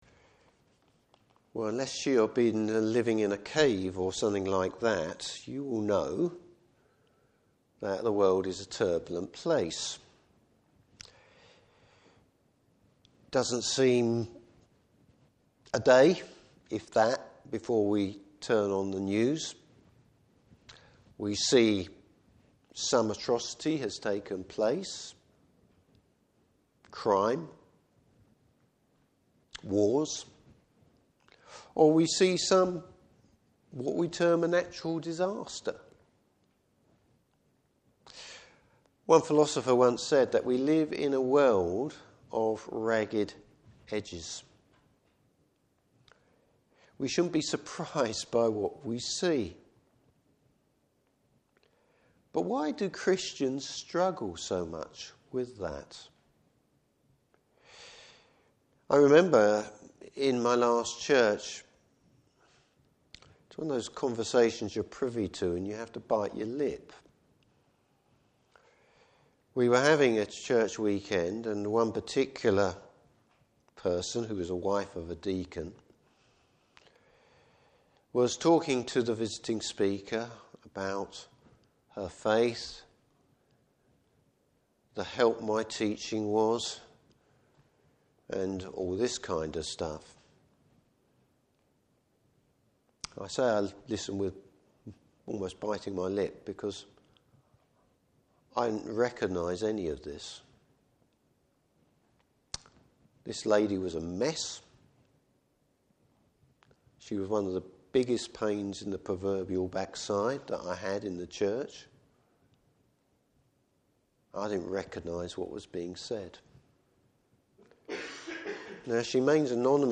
Service Type: Evening Service Confidence in God in a changing world.